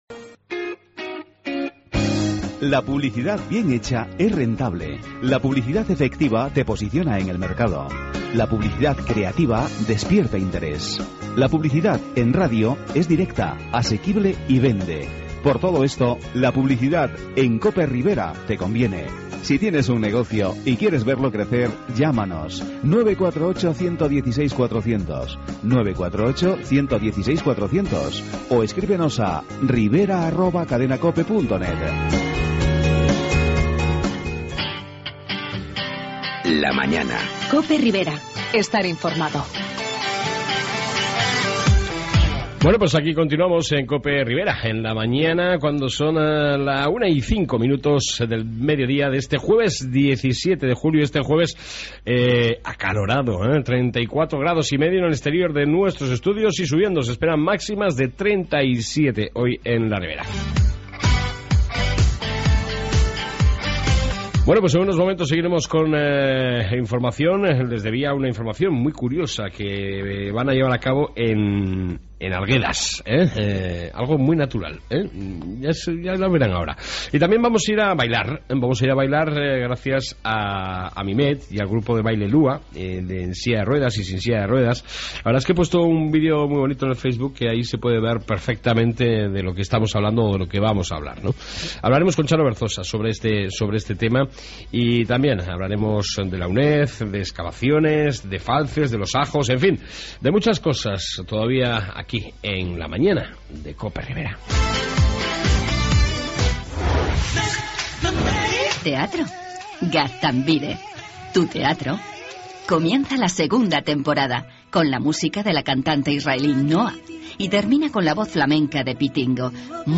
AUDIO: Seguimos en esta 2 parte con la Información y entrevista con el grupo de baile de Amimet Lua